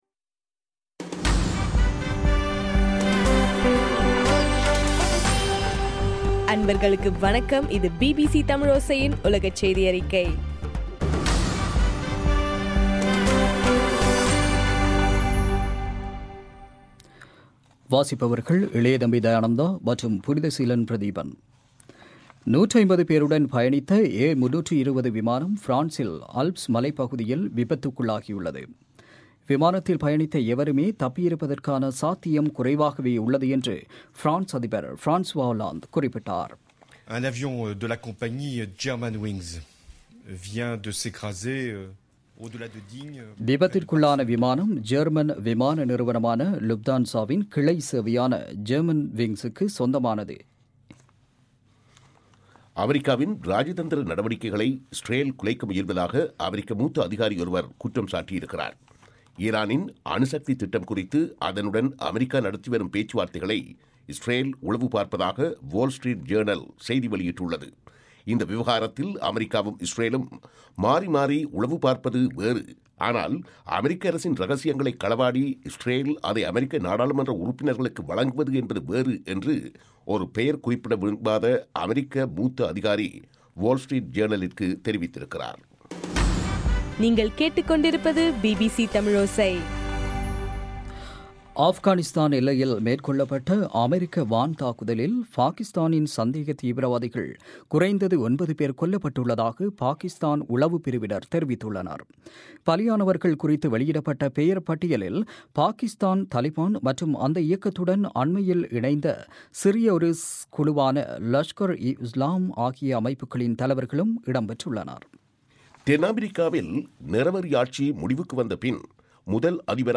இன்றைய ( மார்ச் 24)பிபிசி செய்தியறிக்கை